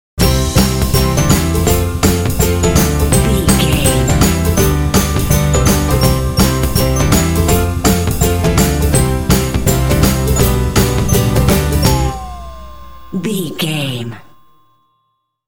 Uplifting
Ionian/Major
joyful
energetic
drums
percussion
acoustic guitar
bass guitar
piano
indie
pop
contemporary underscore